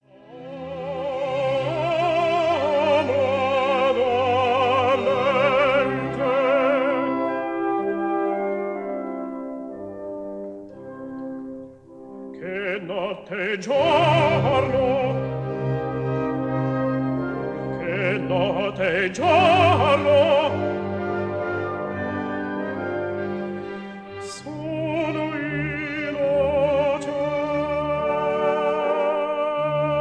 tenor
Recorded in Abbey Road Studio No. 1, London